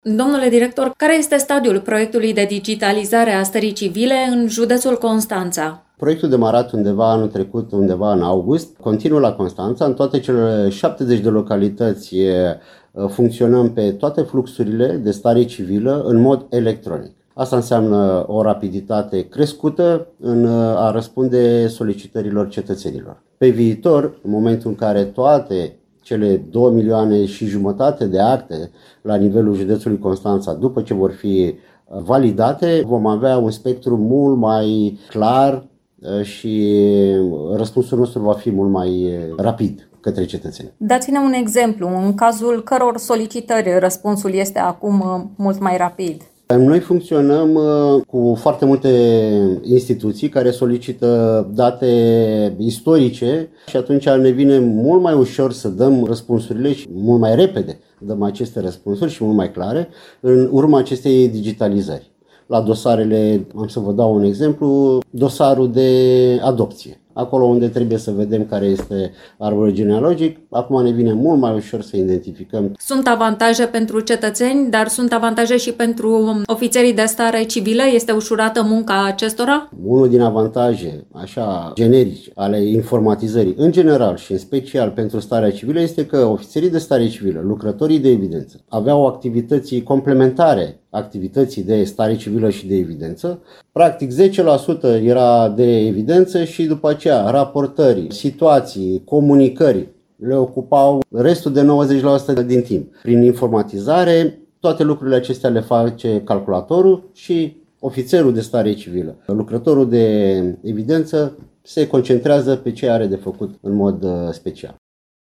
Amănunte despre proiectul derulat cu fonduri europene aflăm de la Constantin Ion, directorul executiv al Direcției Generale de Evidență a Persoanelor Constanța.